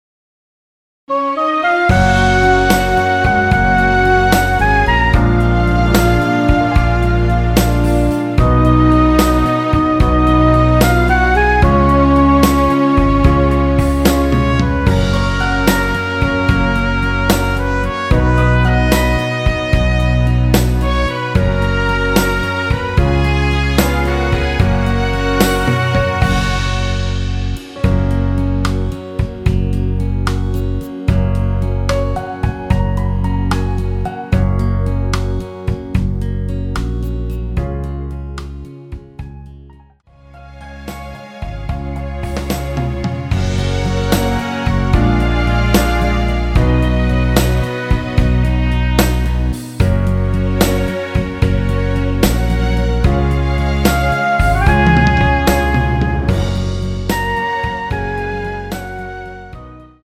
MR입니다.
F#
앞부분30초, 뒷부분30초씩 편집해서 올려 드리고 있습니다.